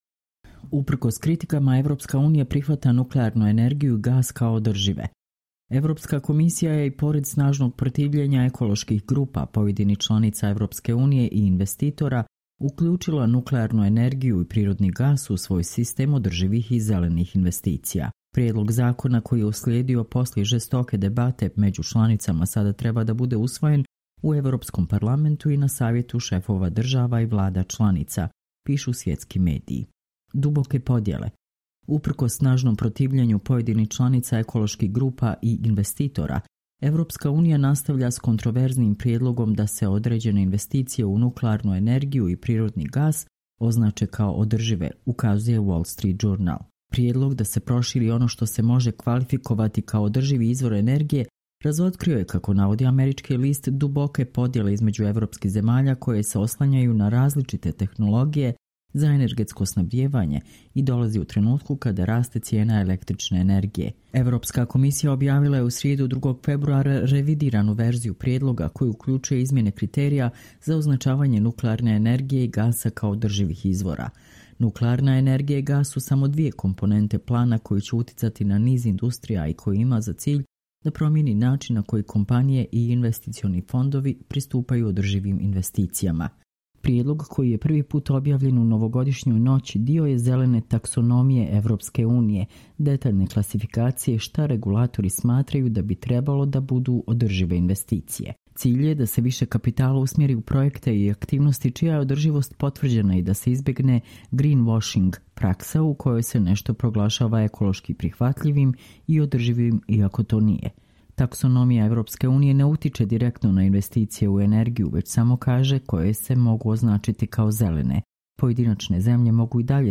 Čitamo vam: Uprkos kritikama EU prihvata nuklearnu energiju i gas kao održive